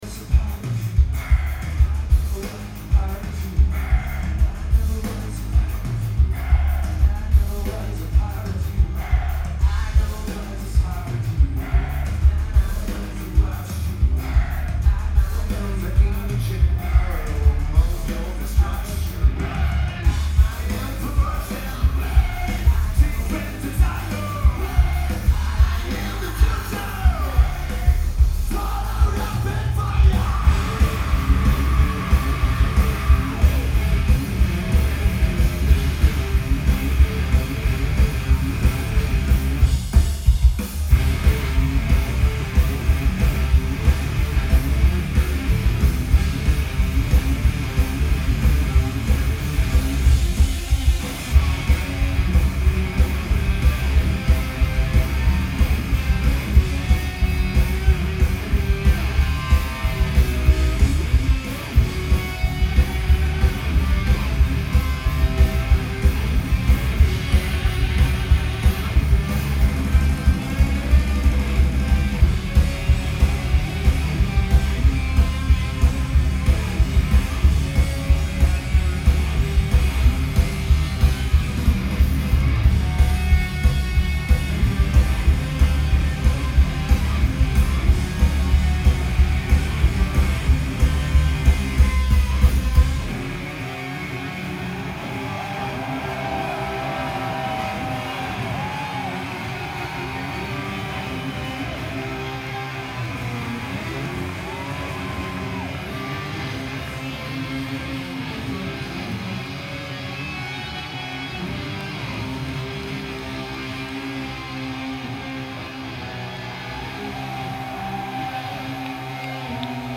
Terminal 5
Great stereo separation on this recording.